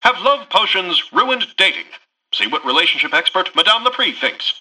Newscaster_headline_27.mp3